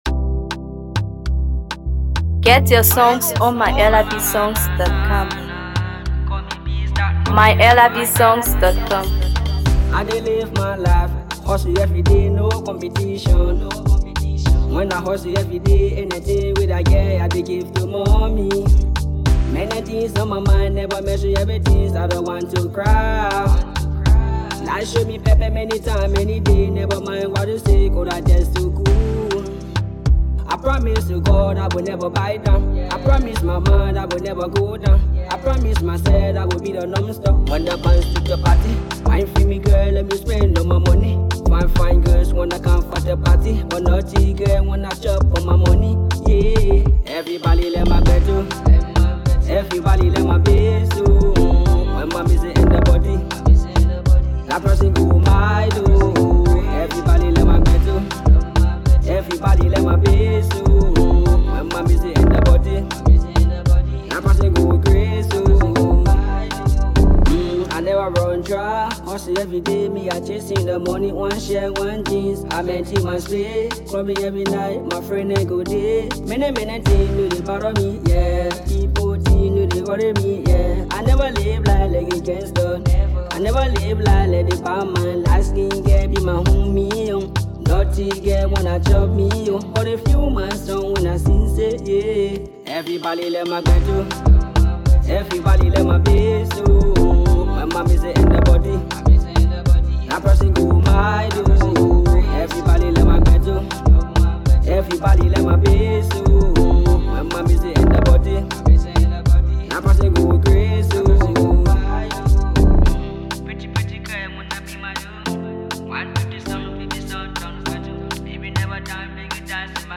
Afro Pop
blends catchy hooks with vibrant beats